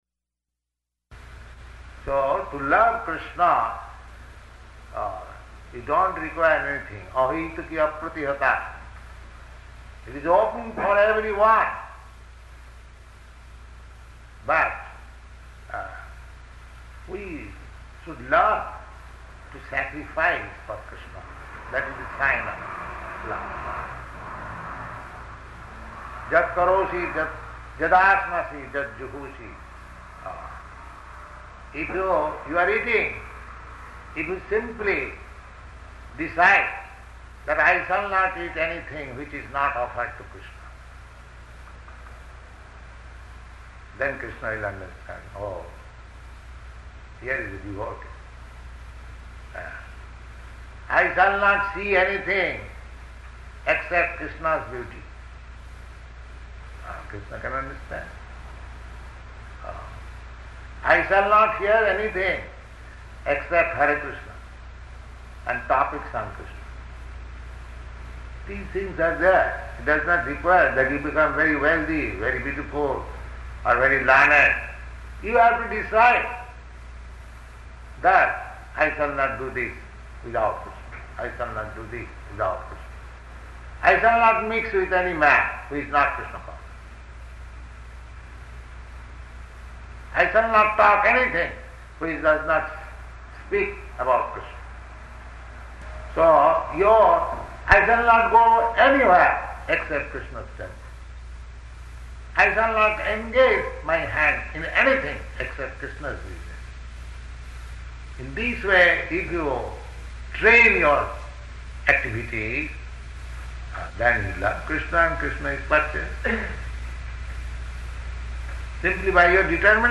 Lecture [partially recorded]
Location: Montreal